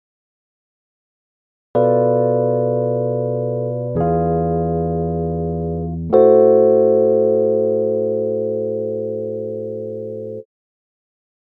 Suite d’accords sans la mélodie, aucun problème :
bm7b5-e7b9-am6.mp3